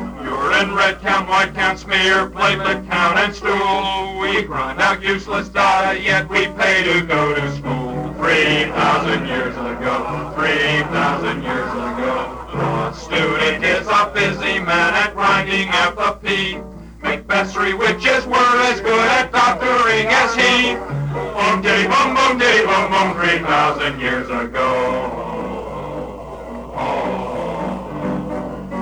Be warned that this is not a professional quality recording,